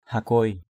/ha-kʊoɪ/ (d.) kê, bo bo = millet.